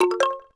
speedwalkon.wav